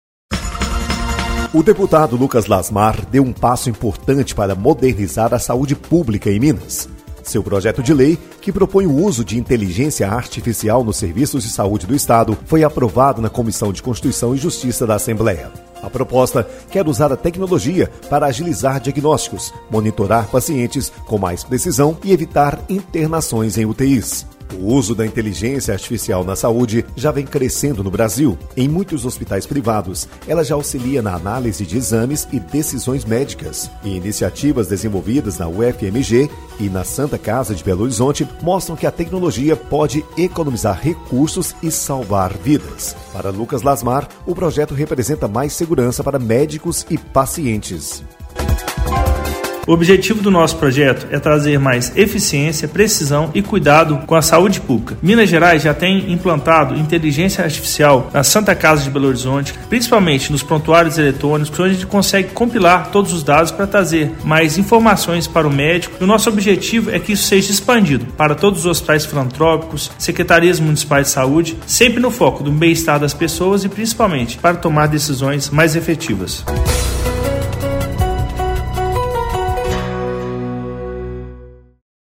Boletim de Rádio